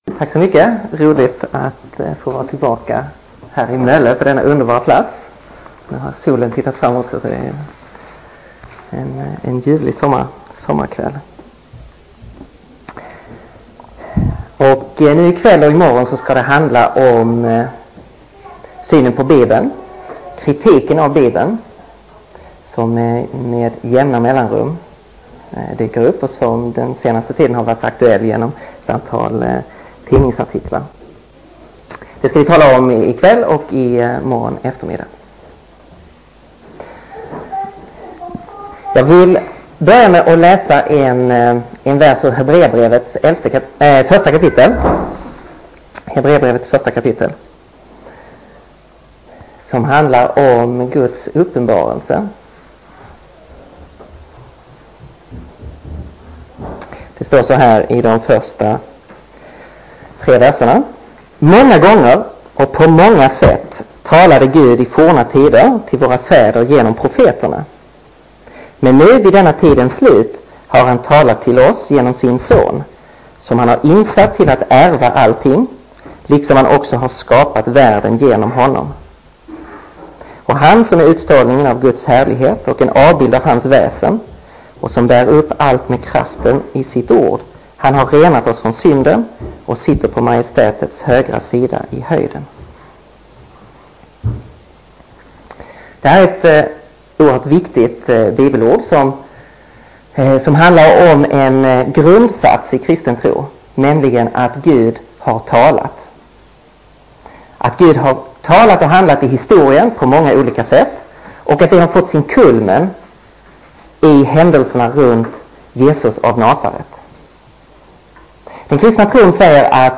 Inspelad under konferensen Kristen inför 90-talet vid Nordiska Labri i Mölle 1996.